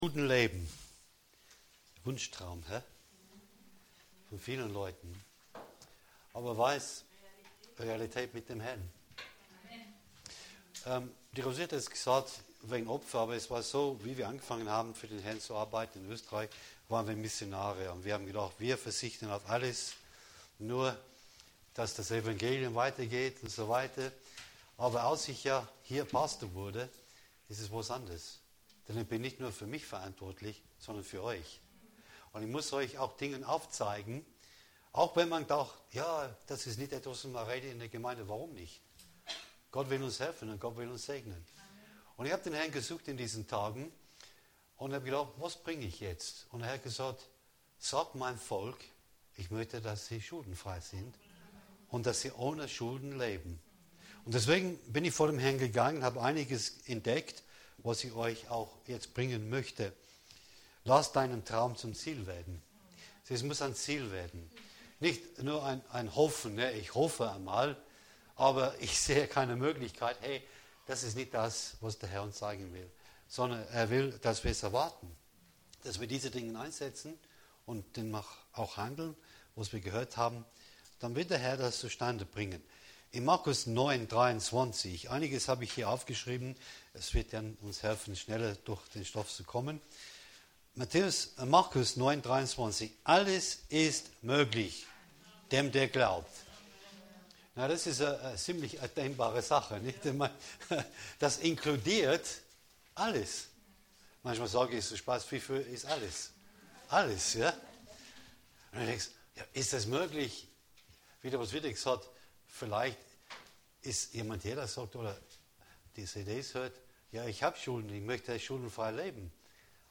Die Serie beinhaltet drei Lehreinheiten zu folgenden Themen: